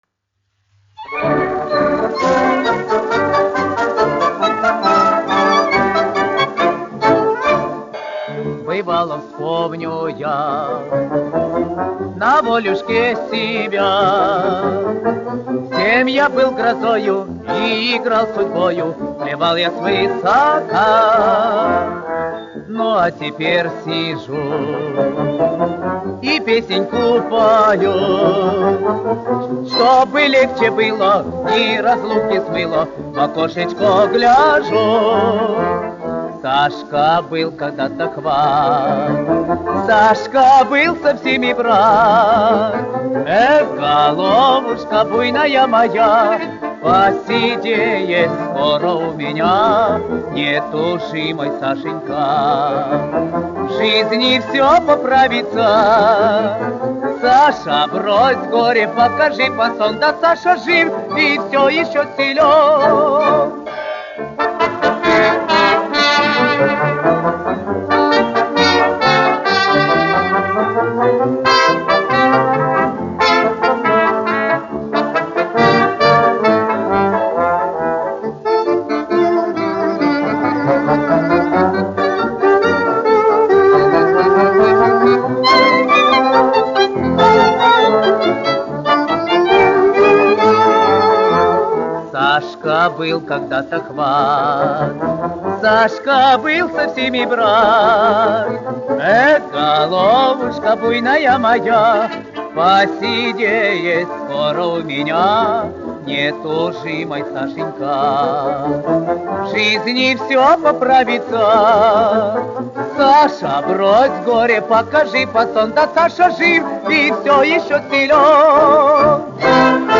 1 skpl. : analogs, 78 apgr/min, mono ; 25 cm
Fokstroti
Populārā mūzika